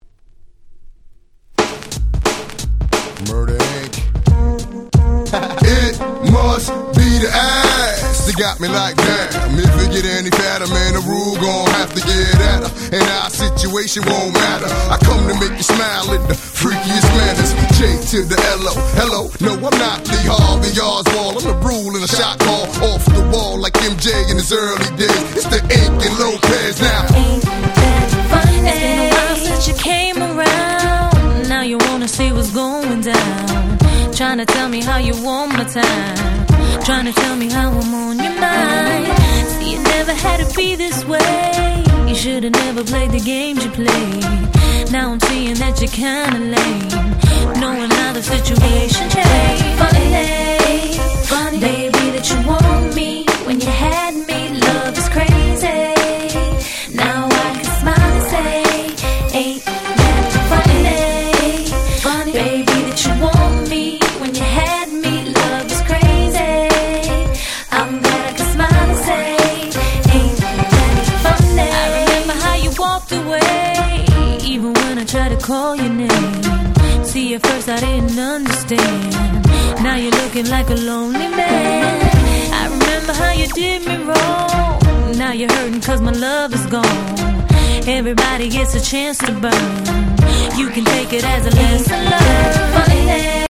02' Super Hit R&B !!